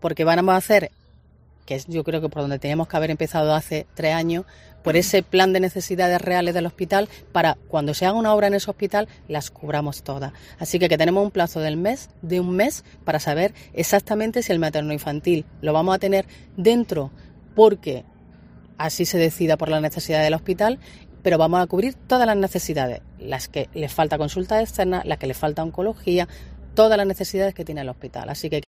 Catalina García, consejera de Salud y Familia